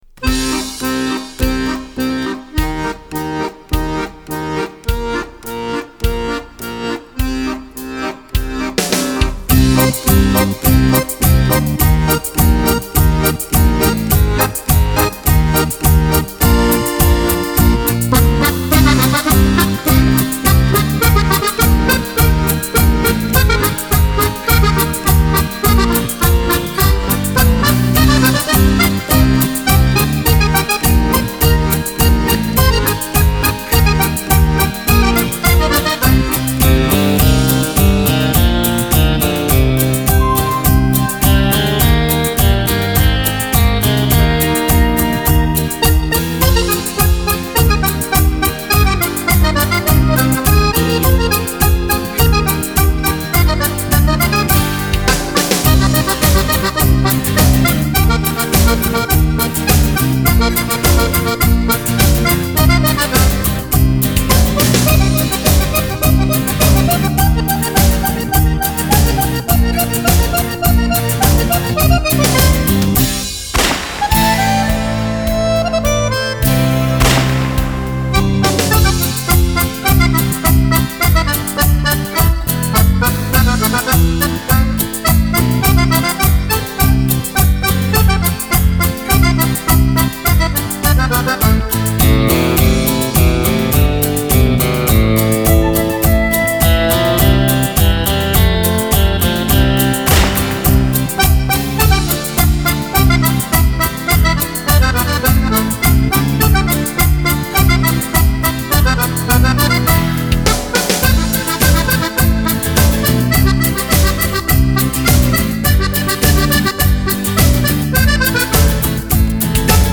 Cumbia
Unico brano strumentale.